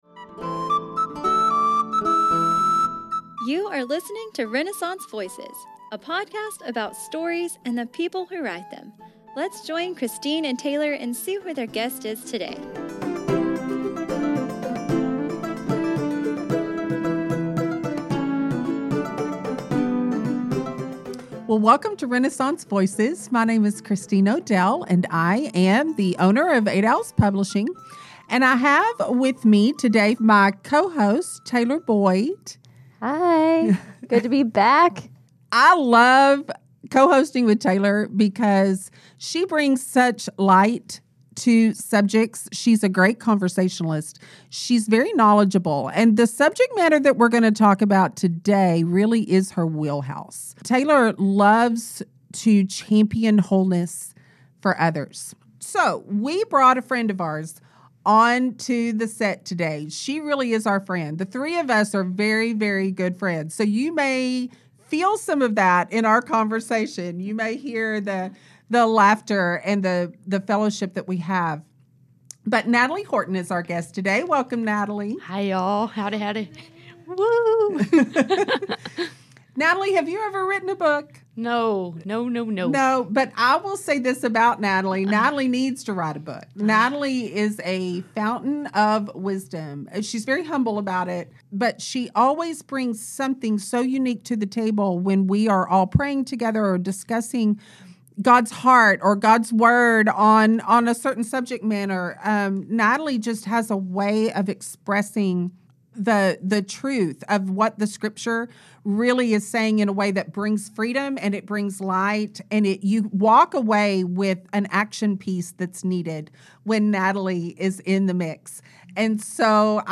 This truly is a casual conversation among friends about the beautiful work Jesus does in our lives to bring us into a place of peace and wholeness.